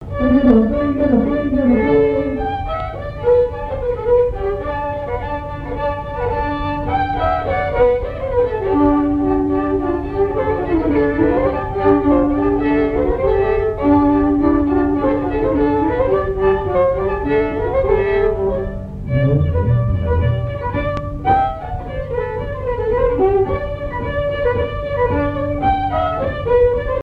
danse : polka lapin
Airs à danser aux violons et deux chansons
Pièce musicale inédite